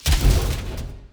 Boss Hitting.wav